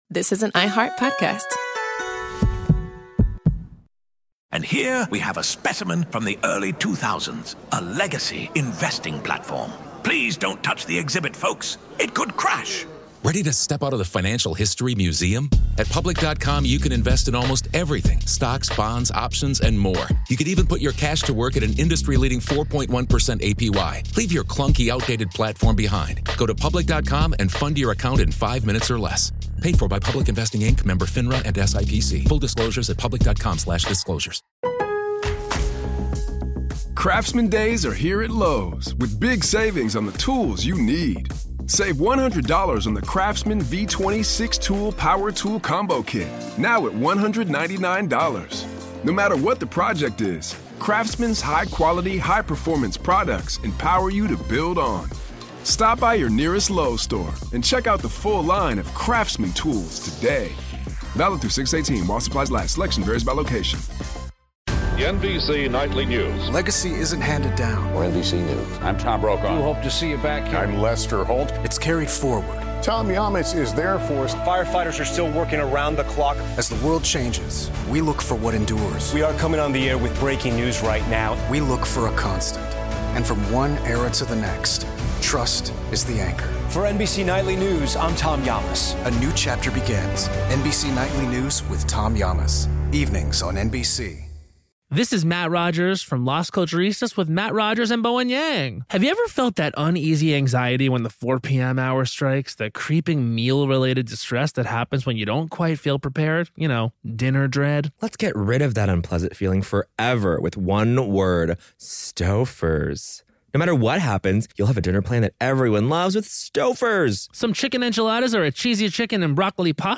The late, great Stephen Ambrose tells the story.